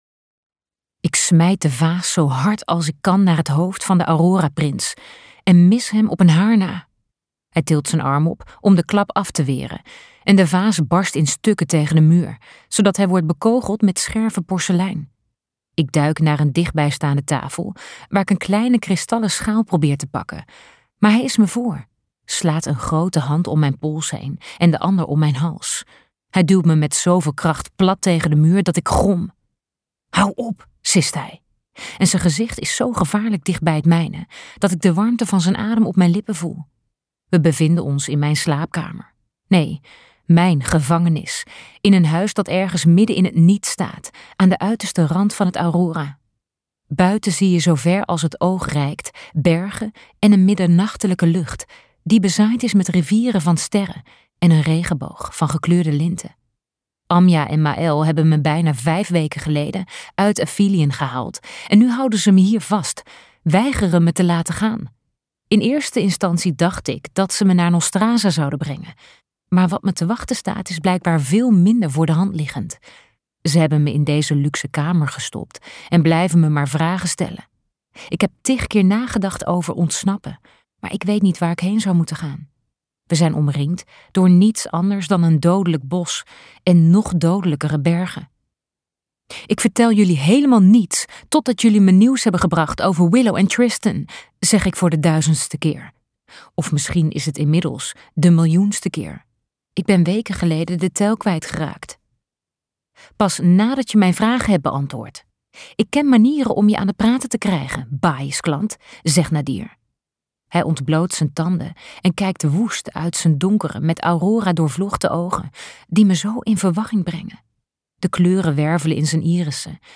Uitgeverij De Fontein | Rule of the aurora king luisterboek